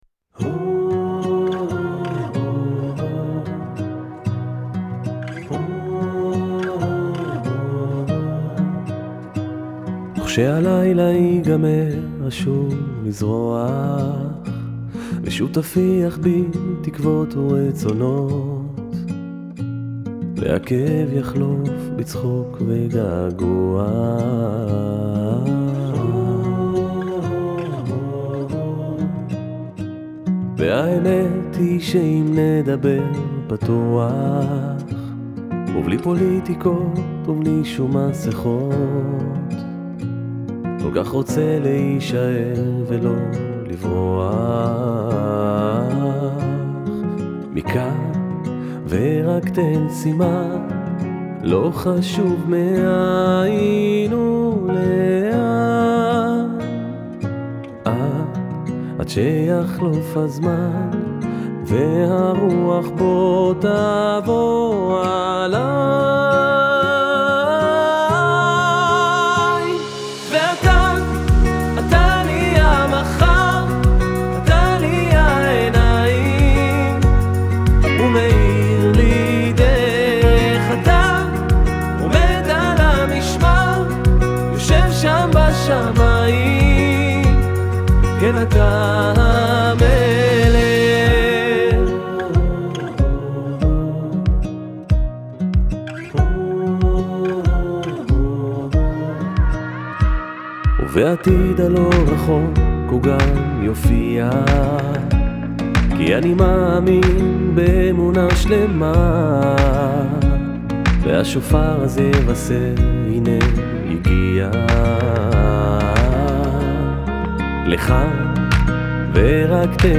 סינגל חדש